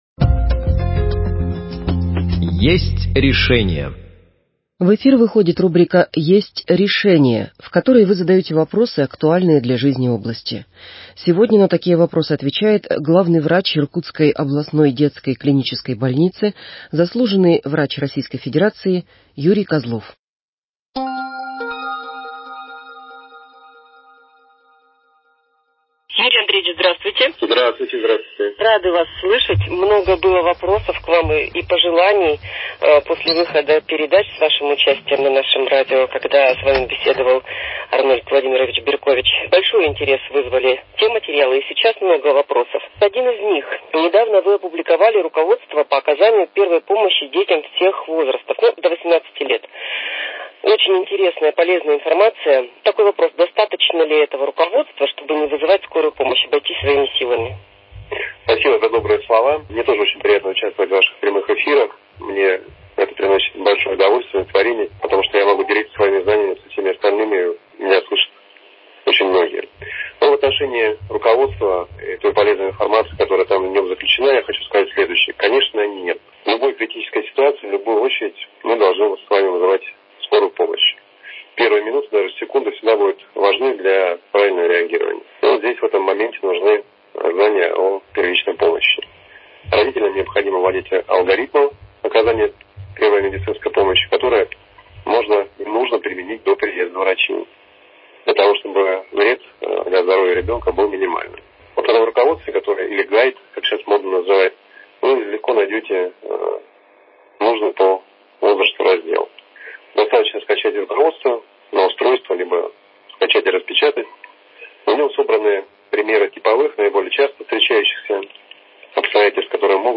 Беседует с ним по телефону